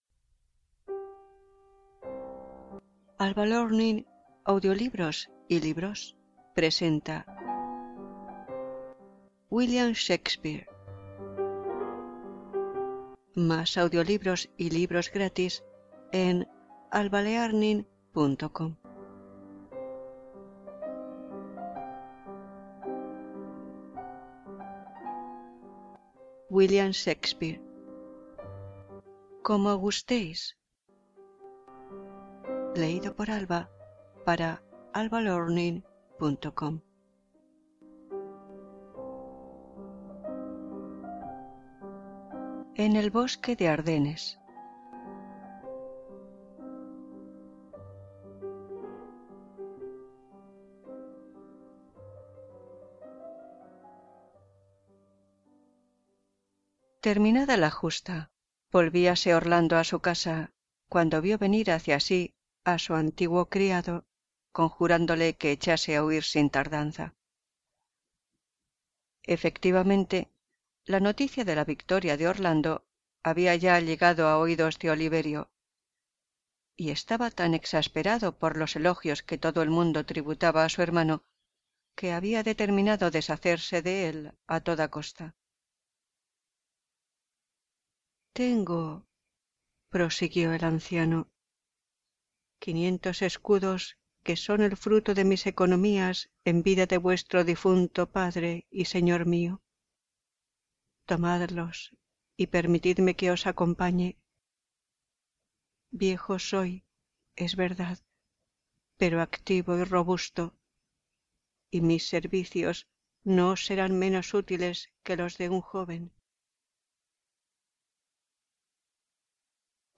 Música: Chopin - Nocturne in C minor